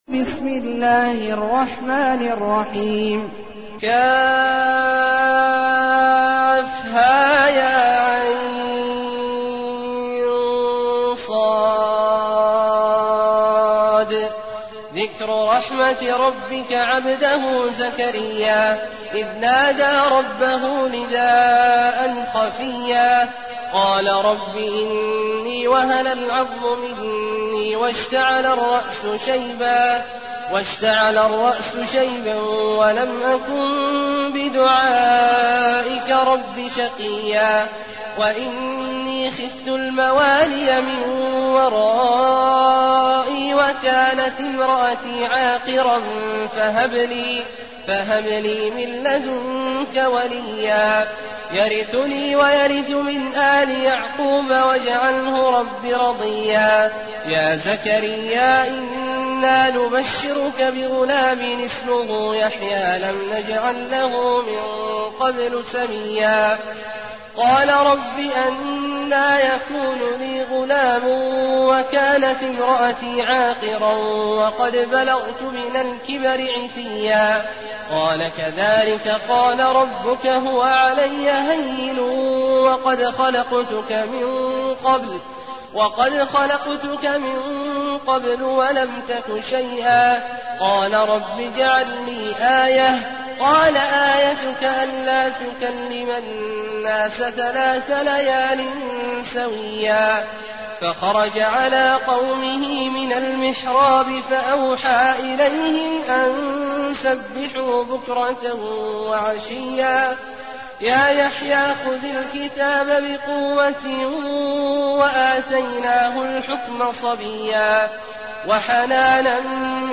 المكان: المسجد النبوي الشيخ: فضيلة الشيخ عبدالله الجهني فضيلة الشيخ عبدالله الجهني مريم